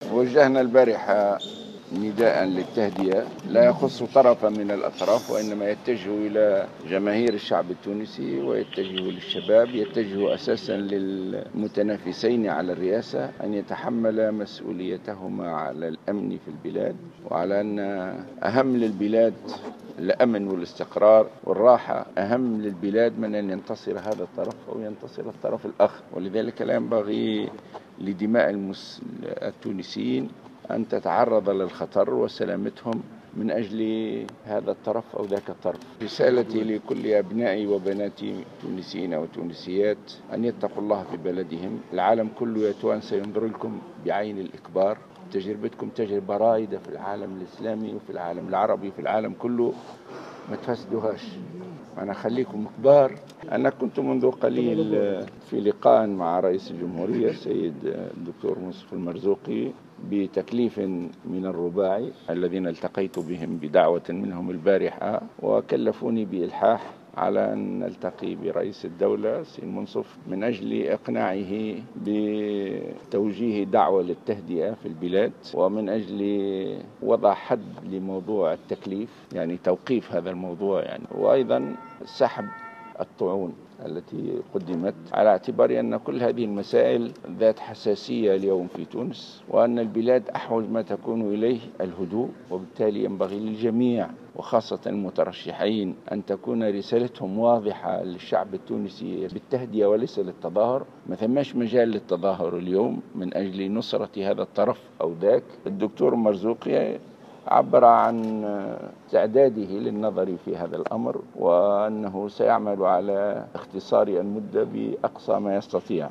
Dans une déclaration à la presse nationale, Rached Ghannouchi, leader d’Ennahdha a indiqué que le quartet parrainant le dialogue national l’a chargé de convaincre Moncef Marzouki de retirer les recours déposés contre le premier tour des élections présidentielles.